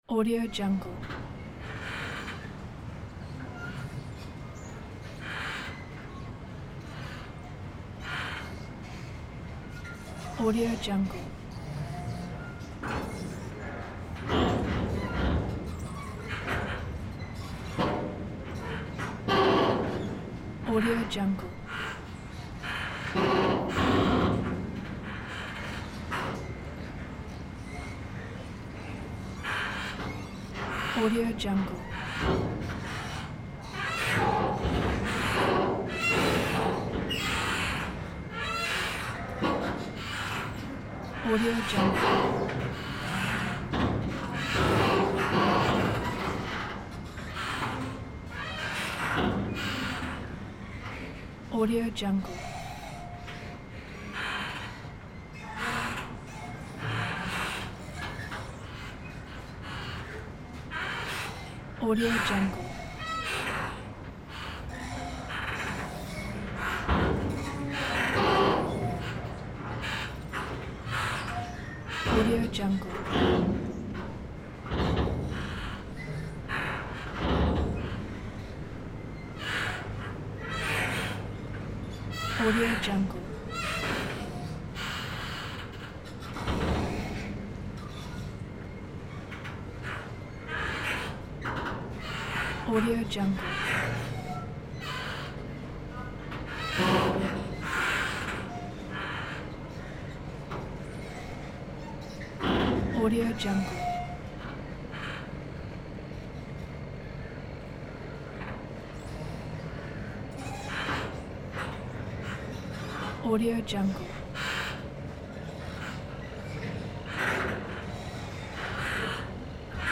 دانلود افکت صدای جیرجیر قایق ها در اسکله
دانلود افکت صوتی شهری
• ایجاد جو آرامش‌بخش: صدای ملایم جیرجیر قایق‌ها در اسکله، حس آرامش و سکون را به بیننده منتقل می‌کند و می‌تواند برای ایجاد جوهای رمانتیک، آرامش‌بخش یا حتی ماجراجویانه در ویدیوهای شما استفاده شود.
Sample rate 16-Bit Stereo, 44.1 kHz